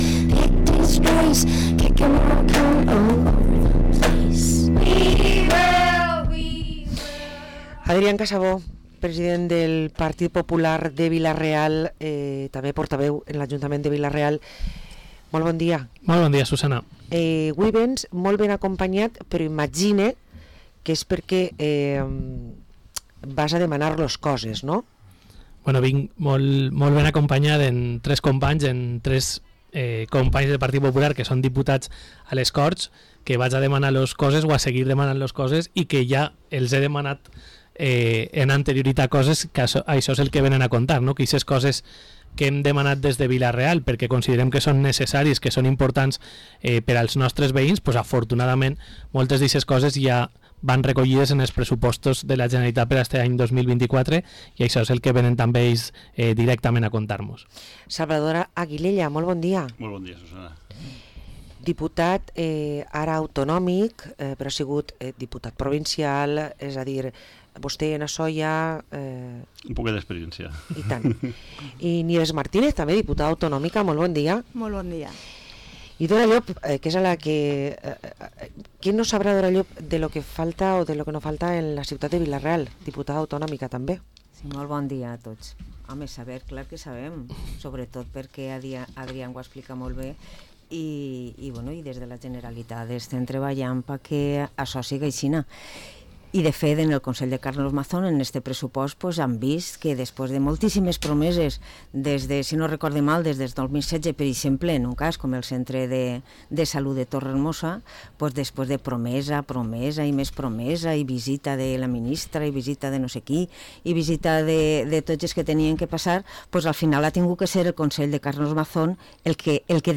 Hui ens acompanya Adrián Casabó portaveu del PP a Vila-real i els diputats autonòmics, Salvador Aguilella, Dora Llop i Nieves Martínez